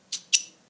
spark.ogg